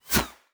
bullet_flyby_fast_06.wav